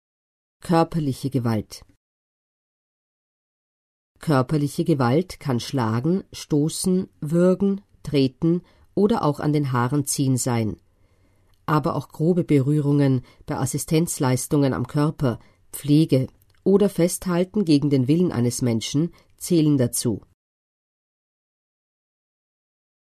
Hier finden Sie die österreichische Broschüre für Frauen mit Behinderungen als Audioversion: „Gewalt, was kann ich tun? Informationen für Frauen mit Behinderungen.“